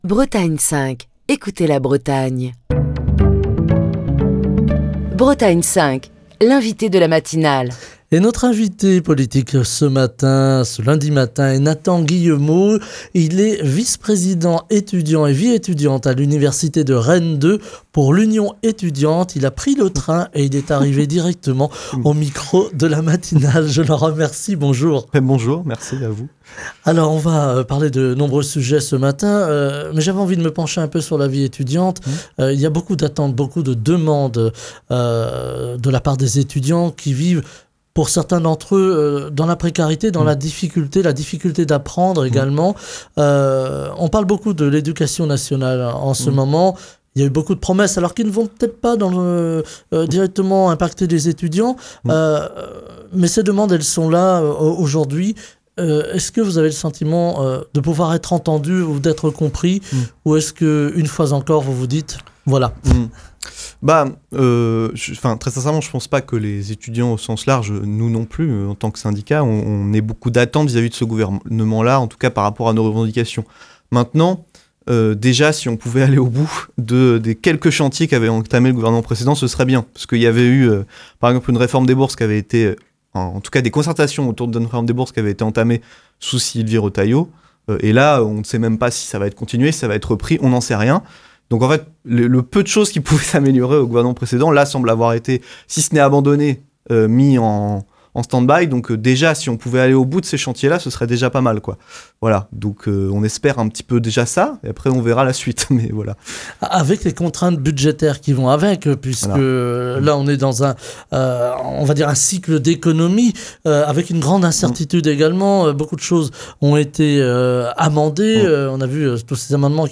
Émission du 18 novembre 2024.